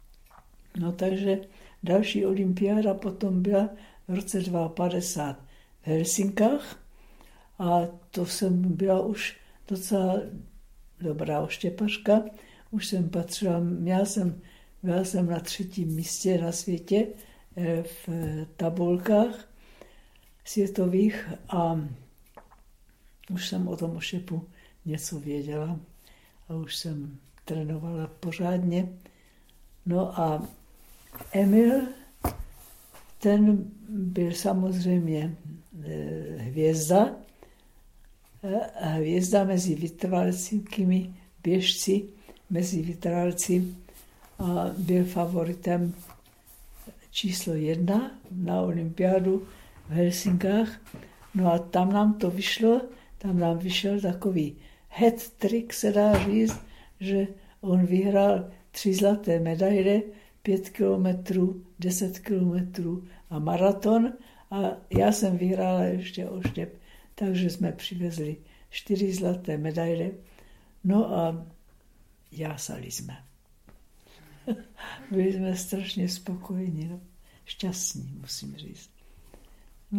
ITW 3 - Helsinki - triplé de conjoints